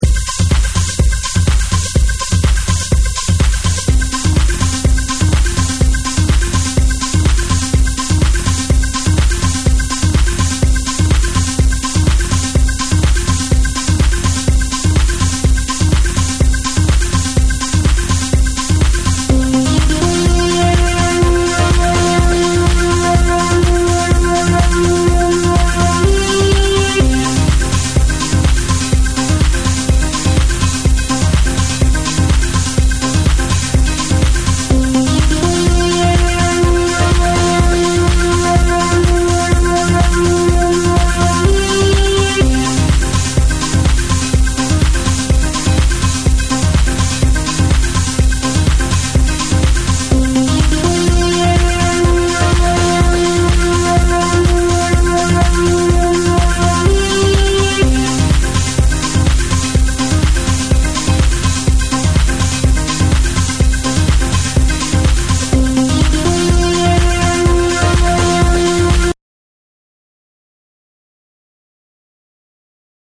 イタロディスコ好きにはたまらないミニマルシンセのリフにボトムの低い4/4キックがジワジワとハメてくれるフロアキラー！
TECHNO & HOUSE